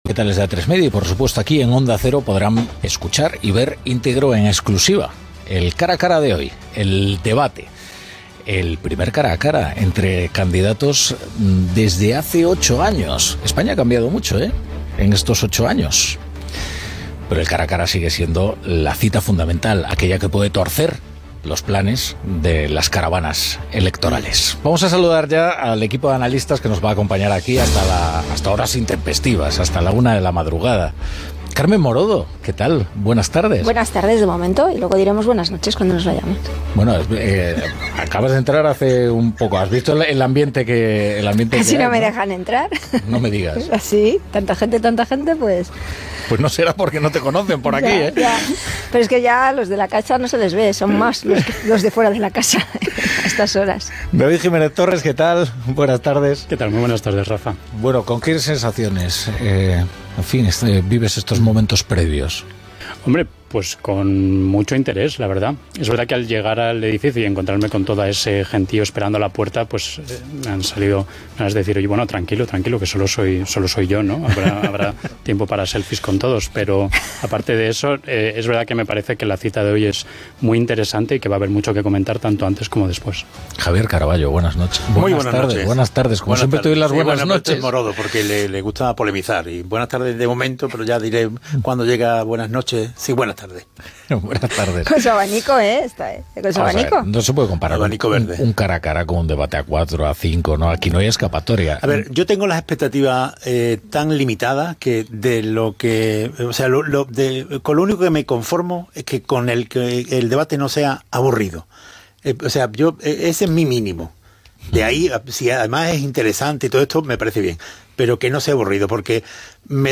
Presentació dels analistes polítics, entrevista a la periodista Ana Pastor que moderarà el debat, diàleg entre els analistes presents a l'estudi i narració dels moments previs als estudis d'Antena 3 Gènere radiofònic Informatiu